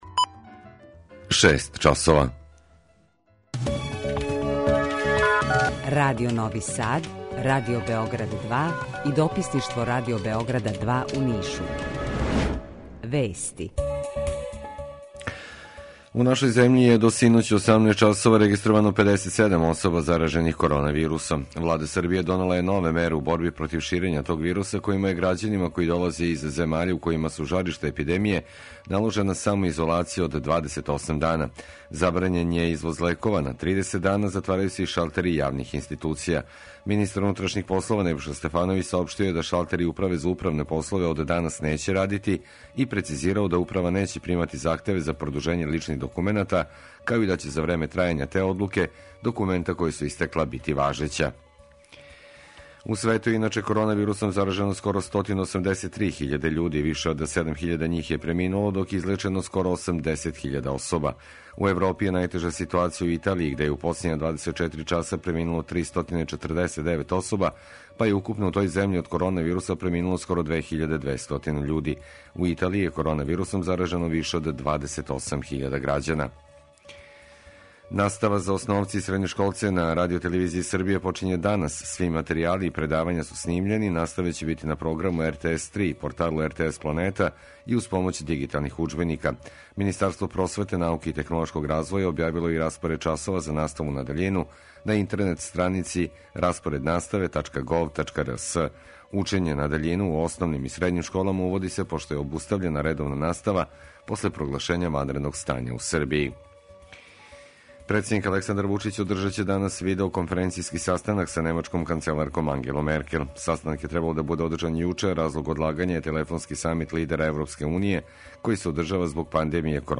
У два сата, ту је и добра музика, другачија у односу на остале радио-станице.